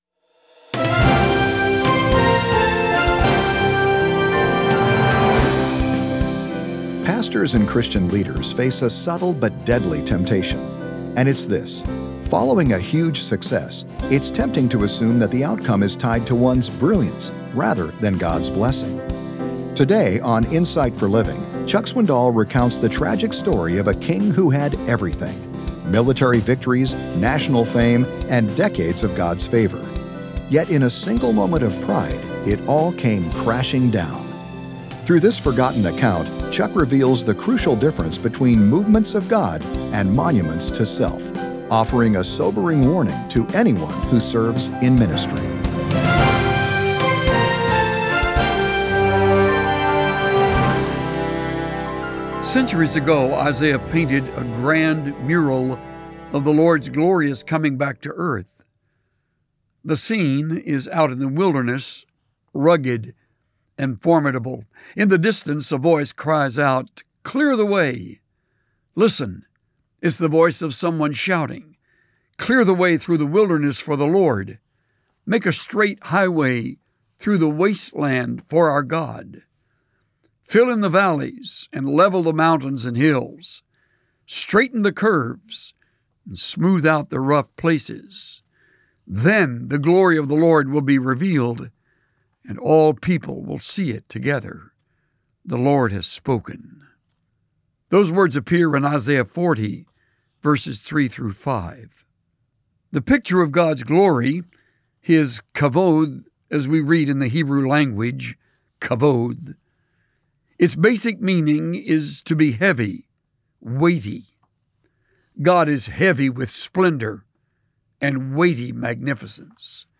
The rise and fall of King Uzziah, recorded in 2 Chronicles 26, is a pertinent example of this tragedy. Tune in to hear Pastor Chuck Swindoll teach on pride, leadership, and God’s glory.